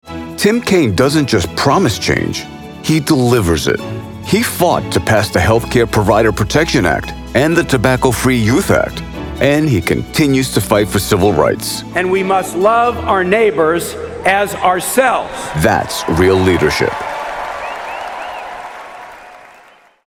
Political Voice Over for Democratic Campaigns
Deep, authoritative, and credible — the voice your audience trusts before the message registers.
Custom-built isolation booth, Sennheiser MKH 416, Audient id14mkII interface, Studio One Pro with iZotope RX. Broadcast quality on every take.